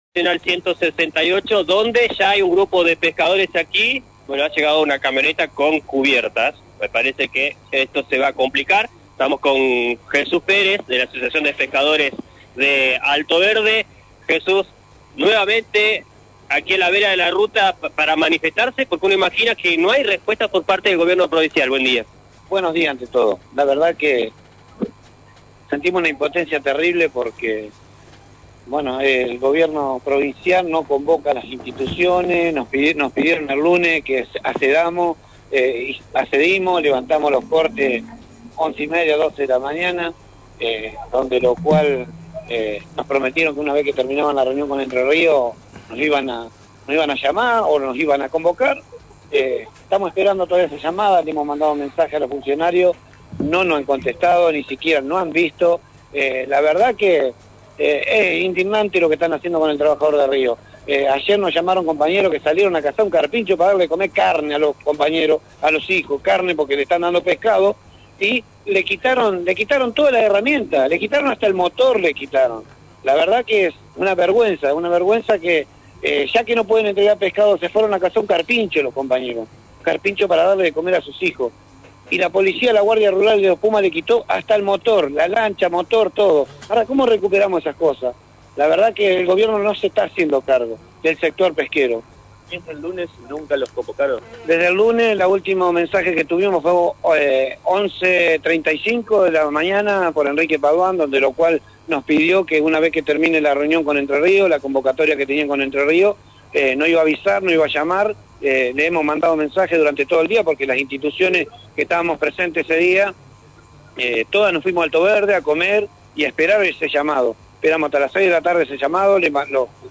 declaró ante la unidad móvil de LT9 que hay mucha importencia y bronca por parte de los trabajadores del río ante la falta de respuesta por parte del gobierno.